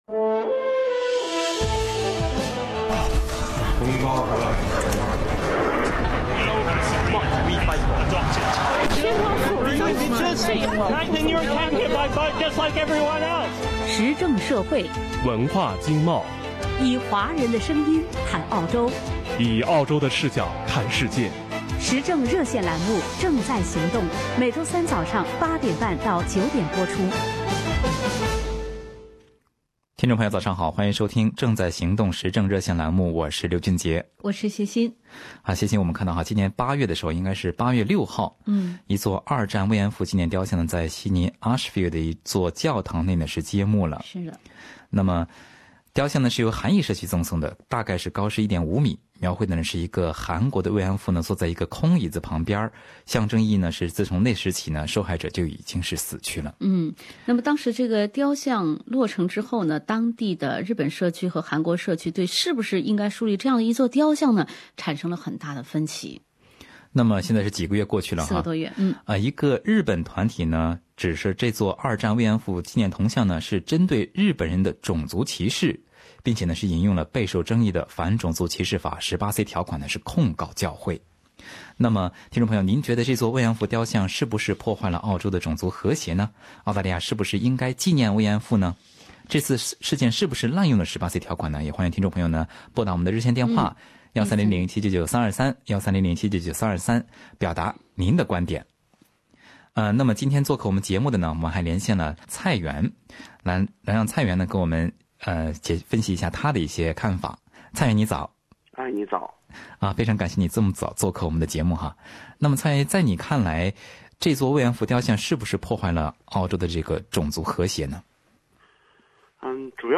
您觉得这座慰安妇雕像是不是破坏了澳洲的种族和谐？SBS特约评论员也作客本期《正在行动》做了点评。